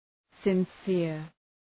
Προφορά
{sın’sıər}